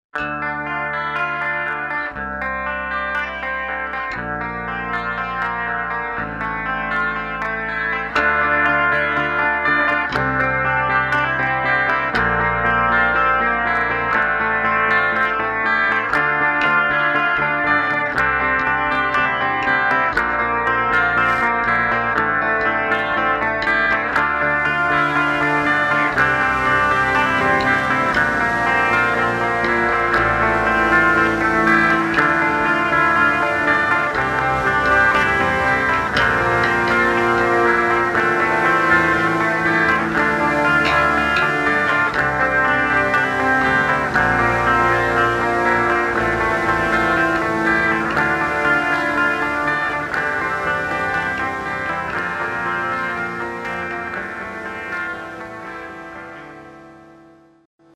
I recorded this with an old-sounding clean effect. You'll catch some distorted and delayed guitars in the background halfway through. Just a few guitars and bass.